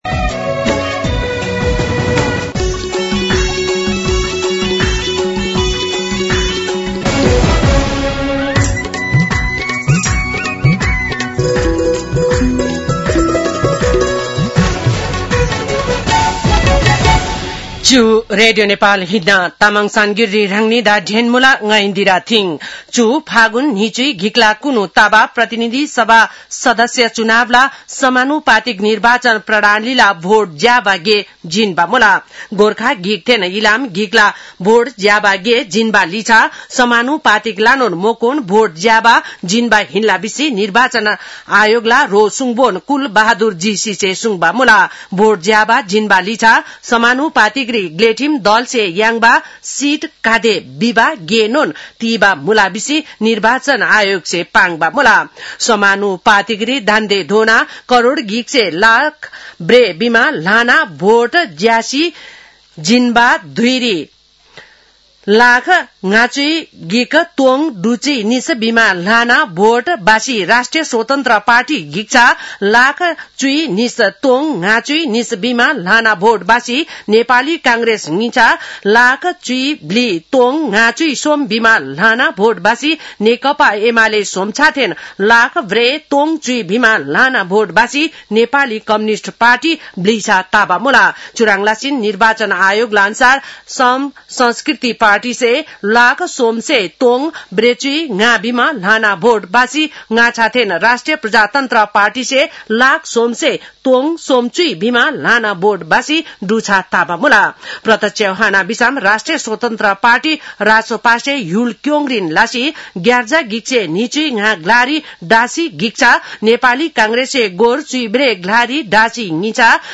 तामाङ भाषाको समाचार : २७ फागुन , २०८२